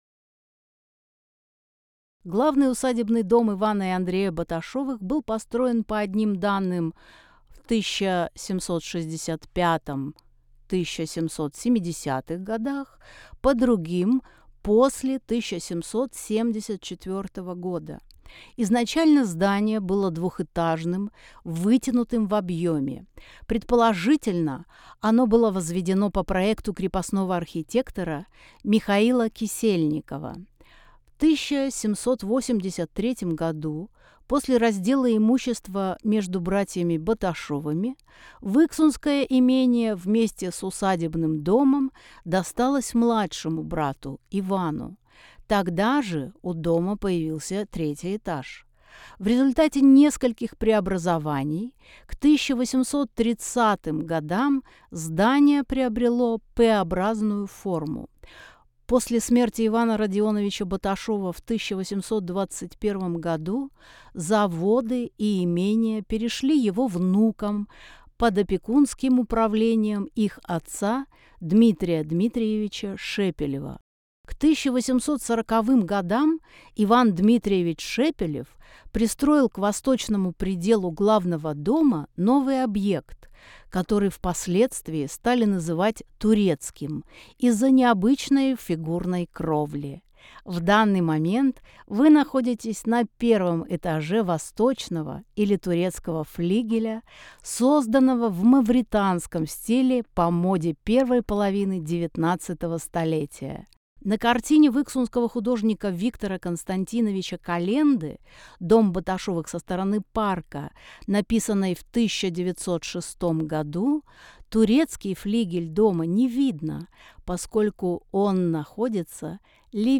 Тифлокомментарии к экспонатам выставки
Аудиогид. 1 этаж. 3 зал. Картина В.К. Коленды Дом Баташевых со стороны парка Аудиогид. 2 этаж. 1 зал.